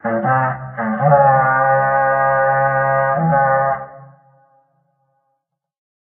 raidhorn_04.ogg